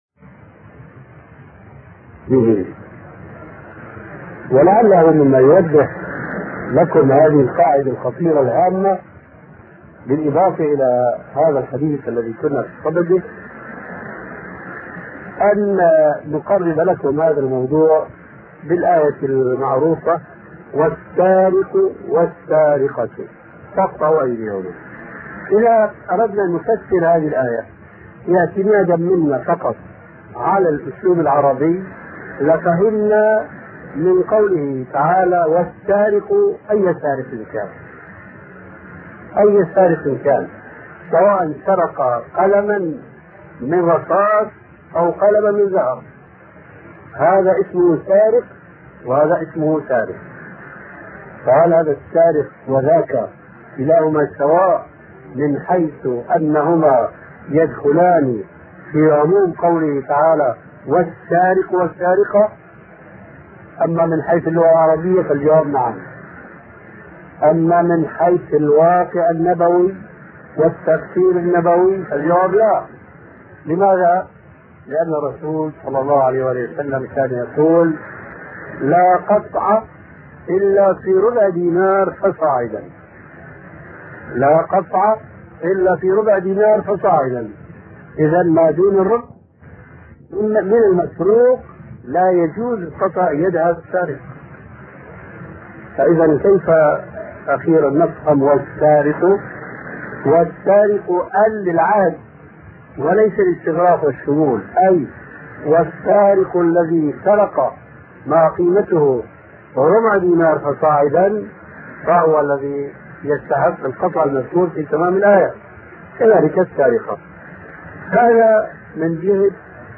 أرشيف الإسلام - ~ أرشيف صوتي لدروس وخطب ومحاضرات الشيخ محمد ناصر الدين الألباني